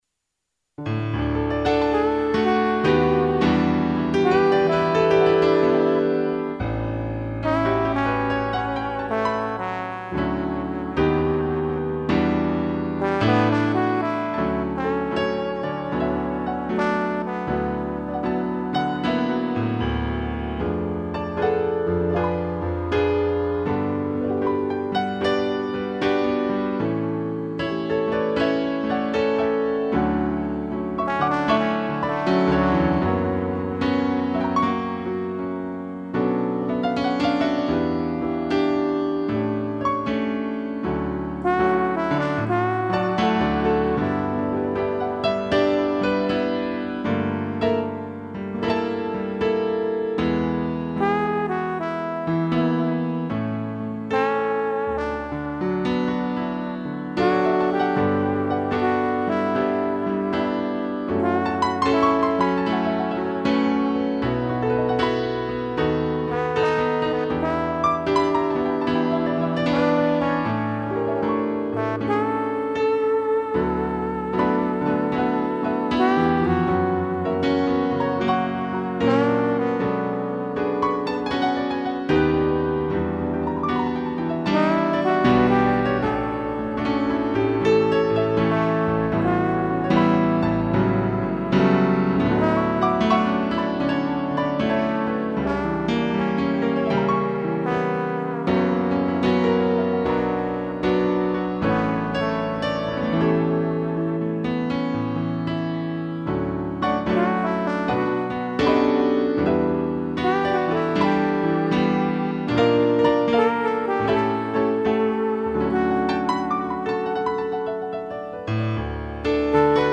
2 pianos e trombone
(instrumental)